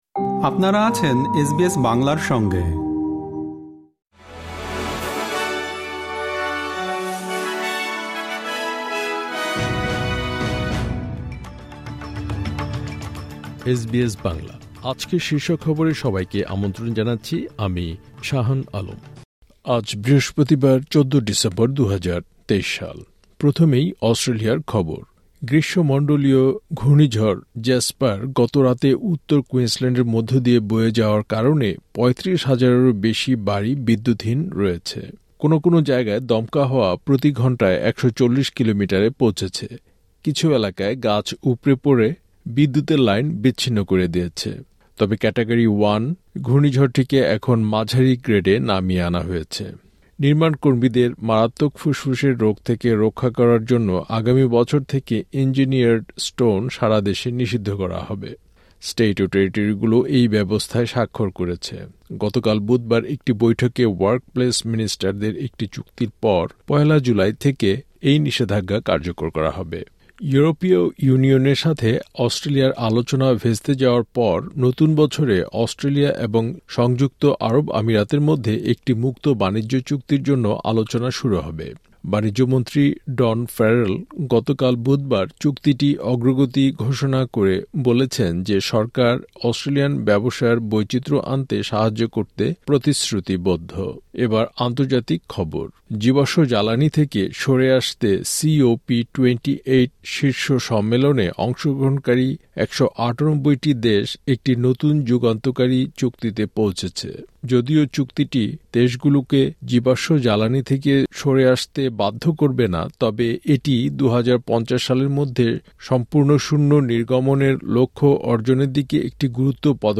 এসবিএস বাংলা শীর্ষ খবর: ১৪ ডিসেম্বর, ২০২৩